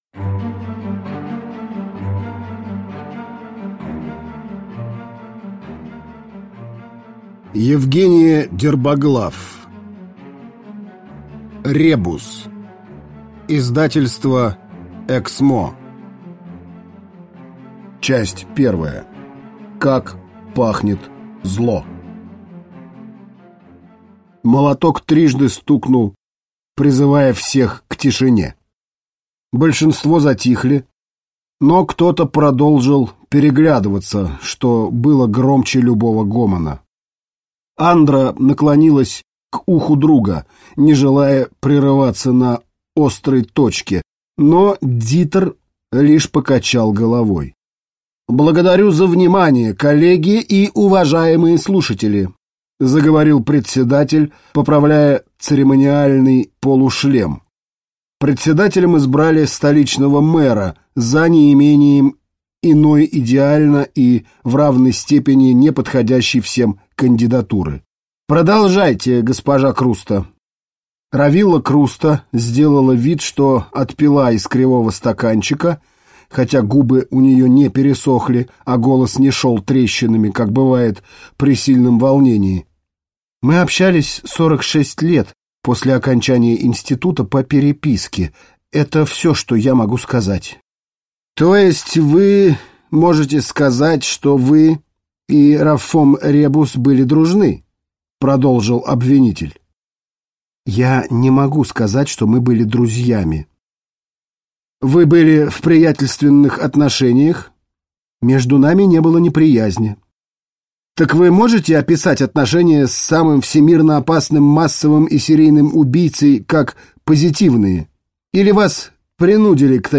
Аудиокнига Ребус | Библиотека аудиокниг